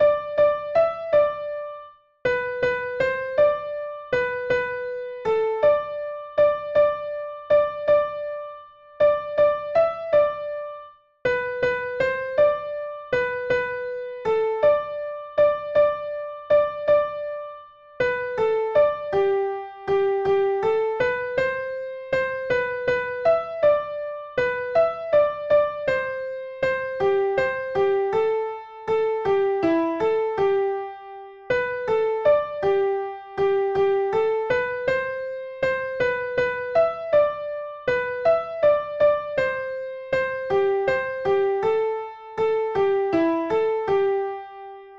Tenor Part